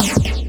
fxcomboldk01.wav